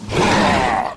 gnoll_warror_attack.wav